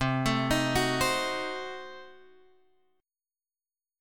Cdim7 chord